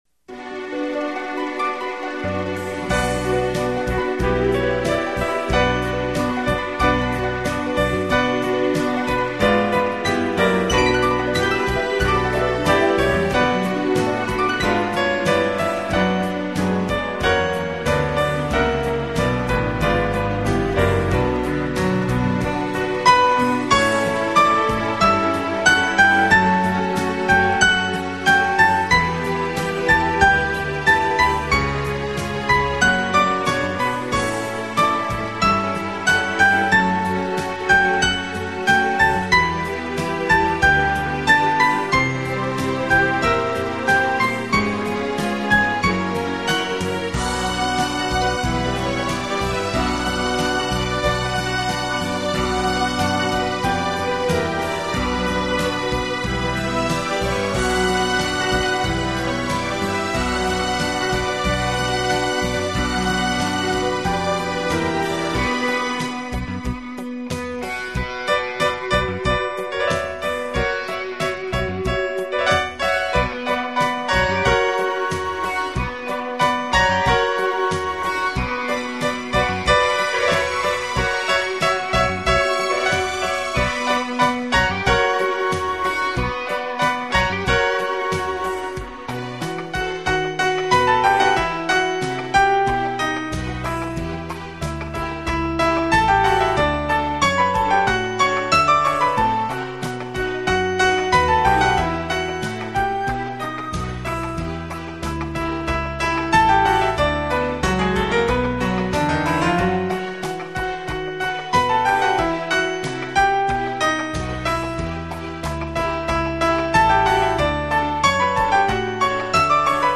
给朋友们送上一个迷人的情调音乐专辑，带领你我进入一个崭新浪漫和华